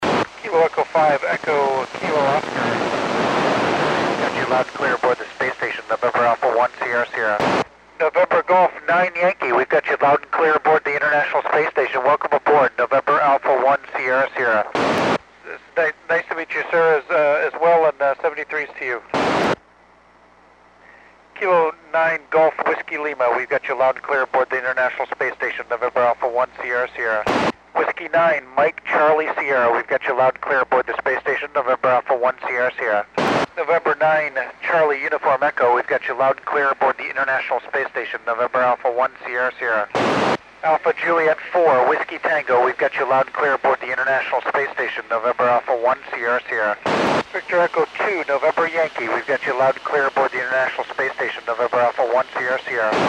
Col. Doug Wheelock (NA1SS) works U.S. and Canadian stations on 08 September 2010 at 2044 UTC.